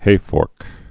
(hāfôrk)